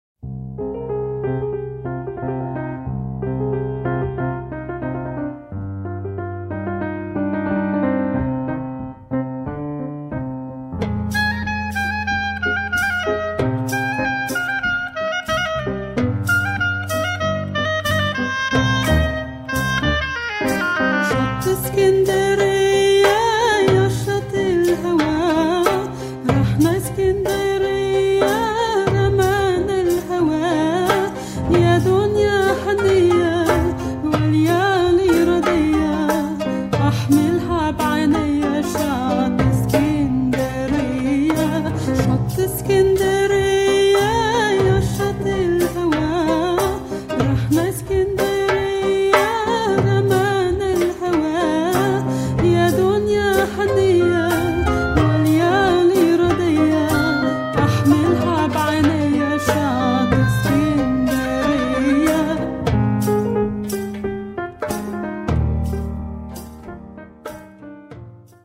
Iraqi Jewish and Arabic Song.